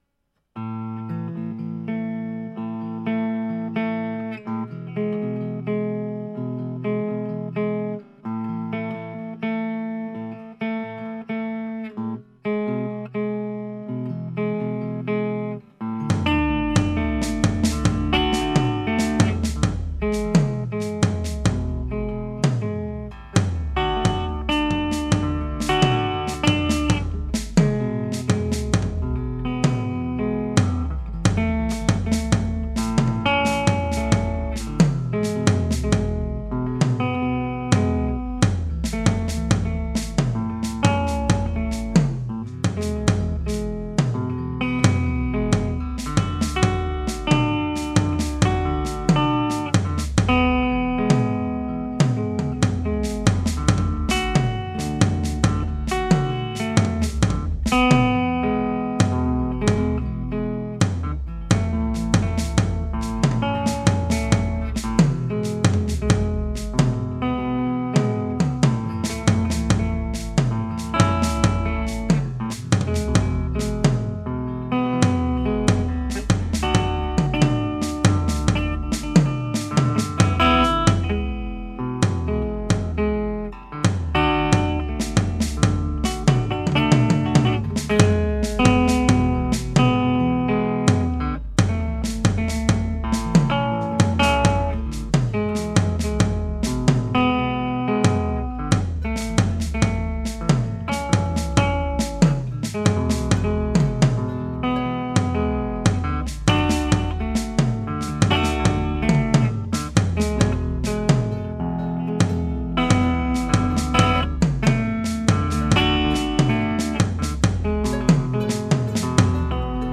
Et une autre enregistrée avec ma Fifth Avenue :